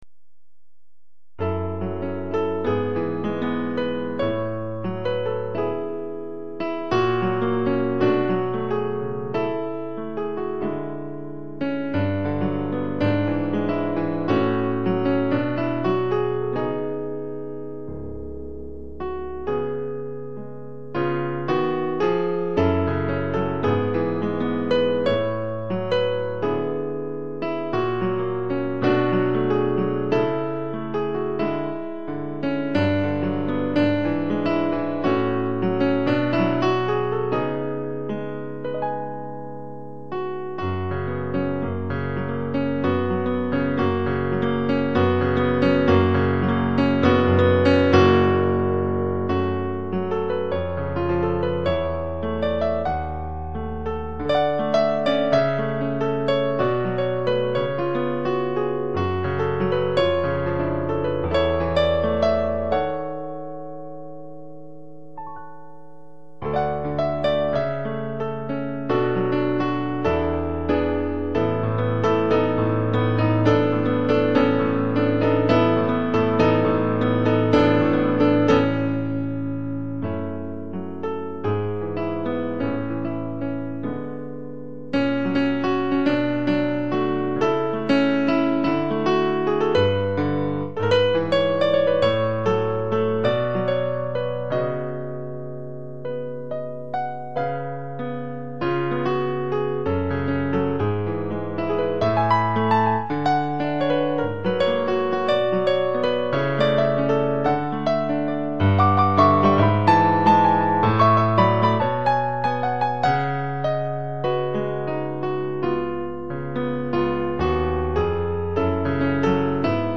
ce thème est ici arrangé d'une manière jazzy